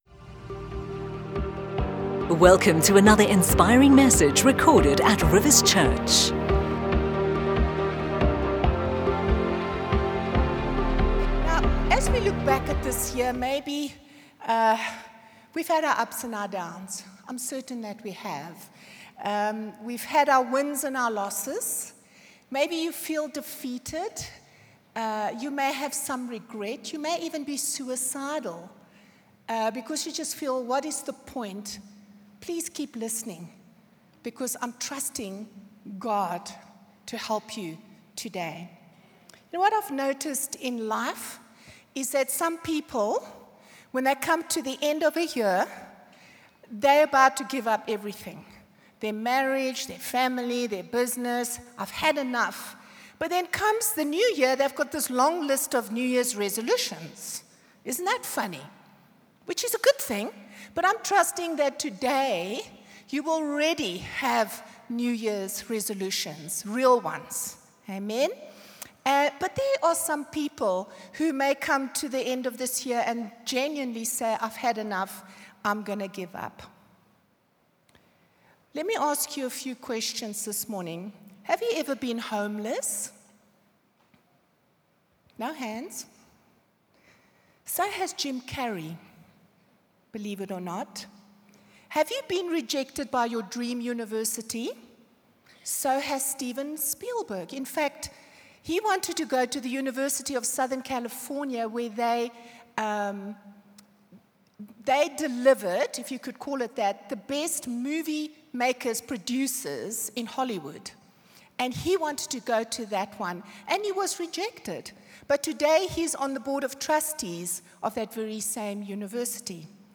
You can download our weekend messages for free!